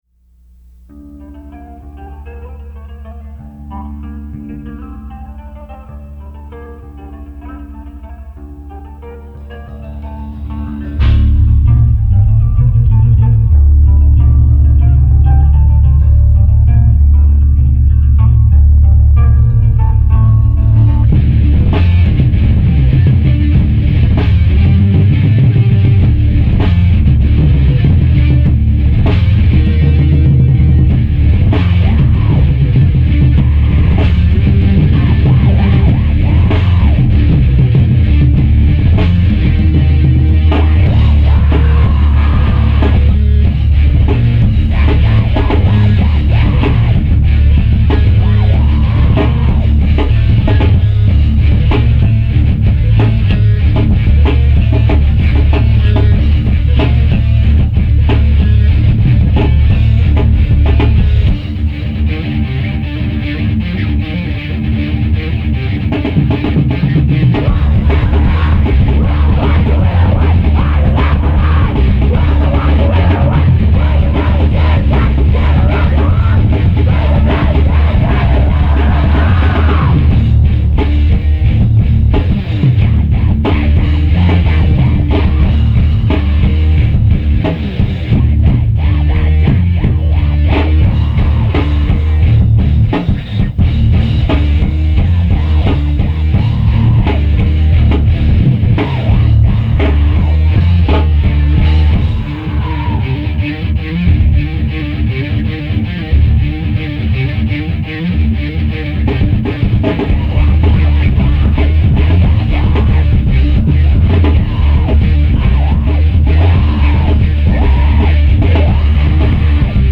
Guitars
Drums
Bass
Vocals
Hardcore , Straight Edge